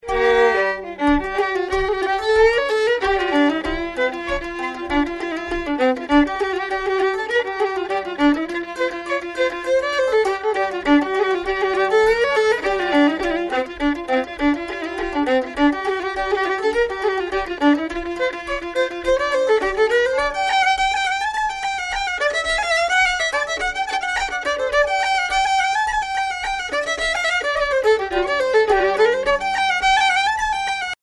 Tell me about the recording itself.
Recorded in Camden Town, London,